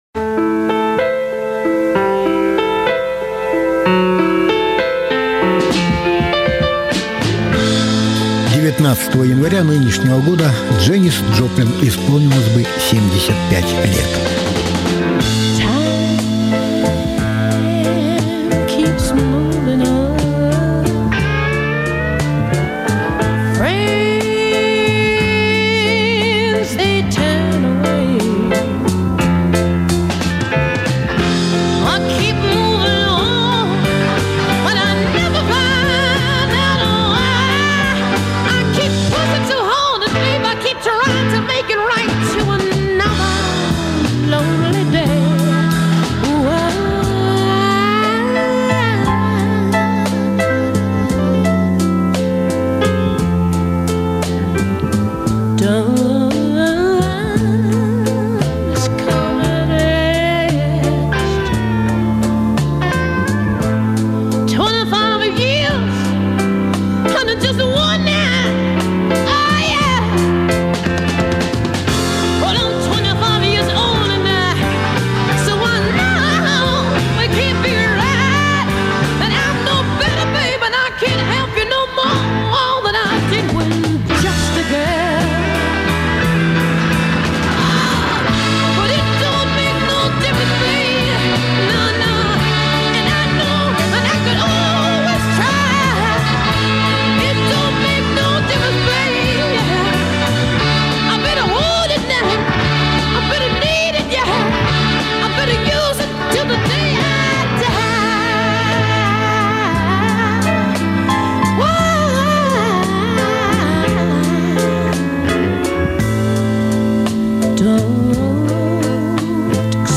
Знаменитые американские блюзовые певицы.